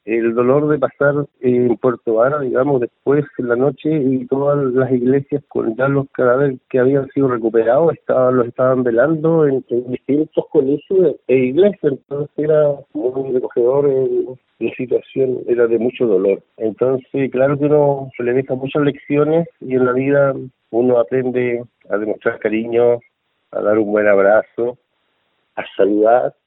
El bombero honorario relató que tenían poca información sobre la contingencia a la que acudían, solo sabían que a la altura del kilómetro 8 de la ruta entre Puerto Varas y Ensenada había un accidente muy grave, por lo que debieron instalar iluminación, además de un hospital de campaña, agregando que el dolor en la comuna lacustre era evidente en iglesias y colegios.